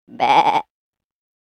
دانلود صدای مزرعه 8 از ساعد نیوز با لینک مستقیم و کیفیت بالا
جلوه های صوتی
برچسب: دانلود آهنگ های افکت صوتی طبیعت و محیط دانلود آلبوم صدای مزرعه روستایی از افکت صوتی طبیعت و محیط